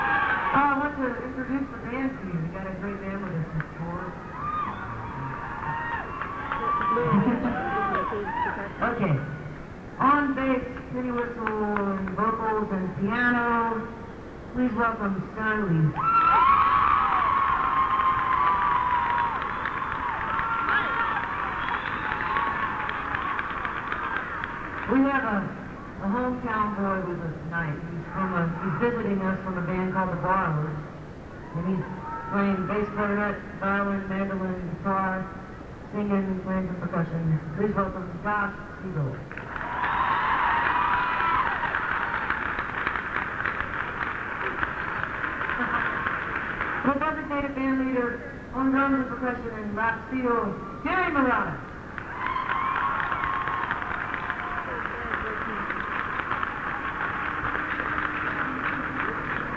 lifeblood: bootlegs: 1997-07-02: the greek theater - los angeles, california
13. band introductions (0:59)